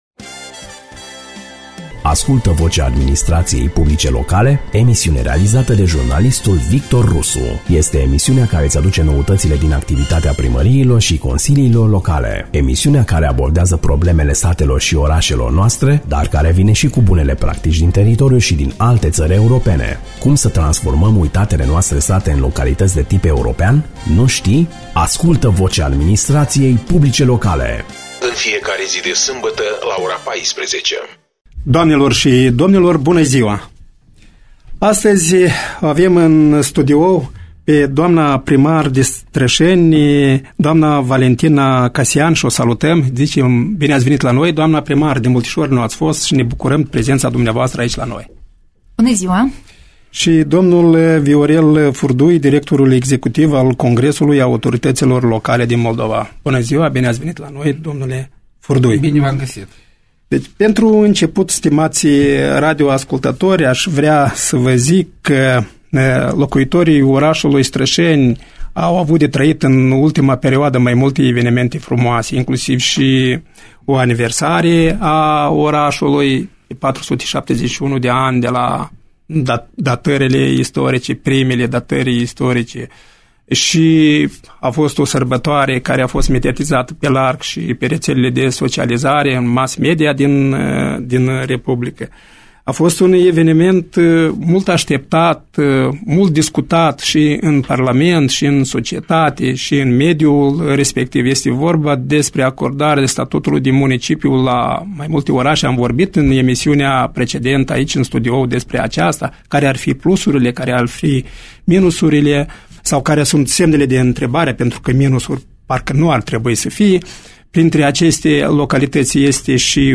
Autortățile publice locale trebuie să dispună de instrumente clare pentru crearea unui mediu de afaceri transparent și echitabil. Este opinia exprimată de invitații emisiunii Vocea APL, difuzată la postul de radio Vocea Basarabiei.